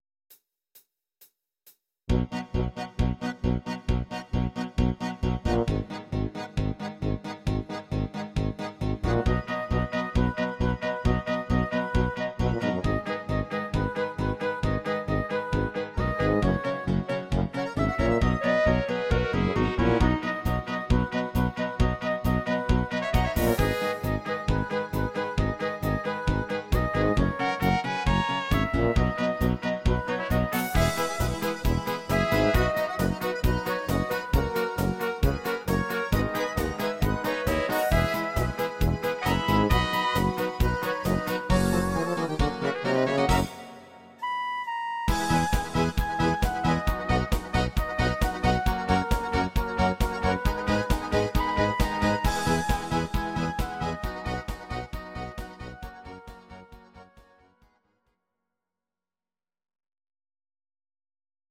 These are MP3 versions of our MIDI file catalogue.
Please note: no vocals and no karaoke included.
Oberkrainer sound